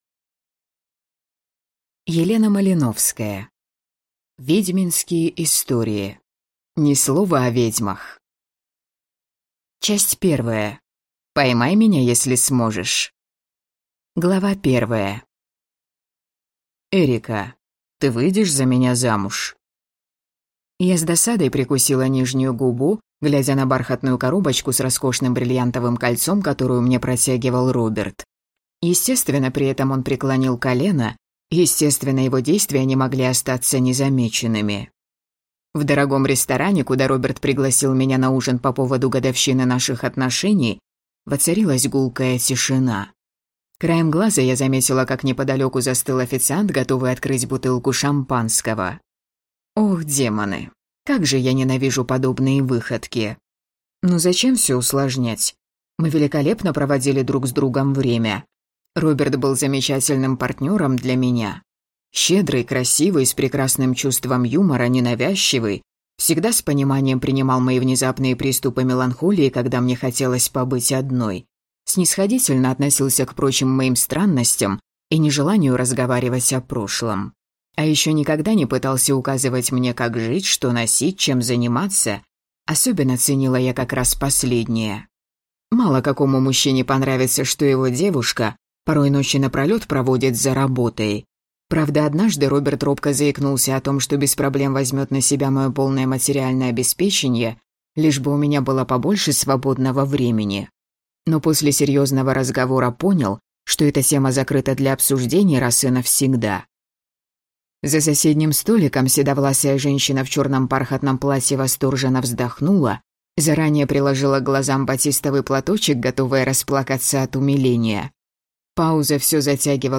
Аудиокнига Ведьминские истории. Ни слова о ведьмах!